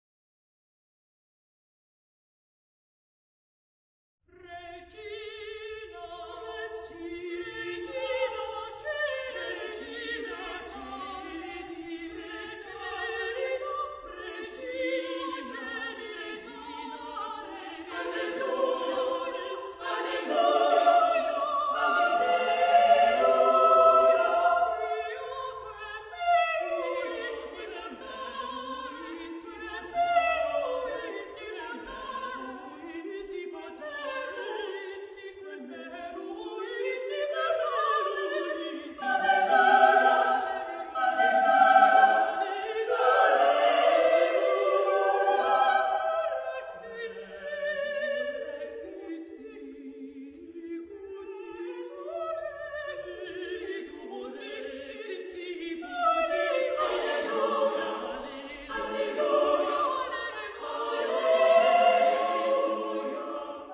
Genre-Style-Forme : Sacré
Type de choeur : SSAA  (4 voix égales de femmes )
Solistes : Soprano (1) / Alto (1)  (2 soliste(s))
interprété par The Schütz Choir of London dirigé par Roger Norrington